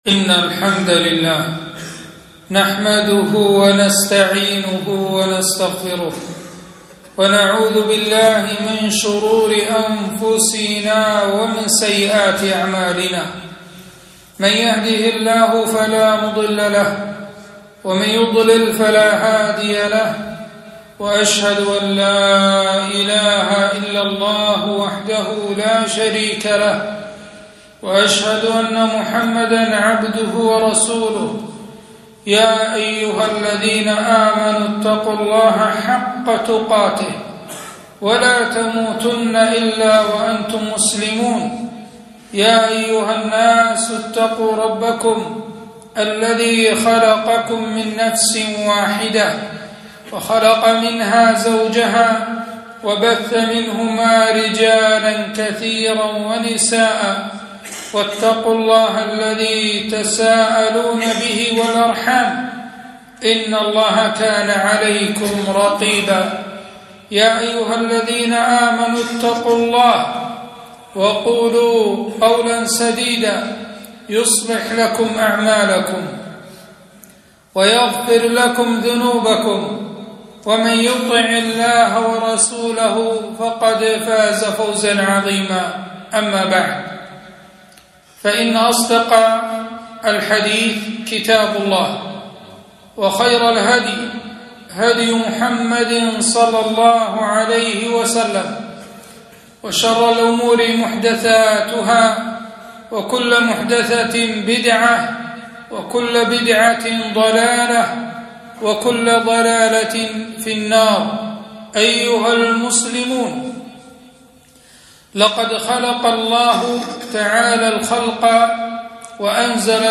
خطبة - التحذير من الفساد في الأرض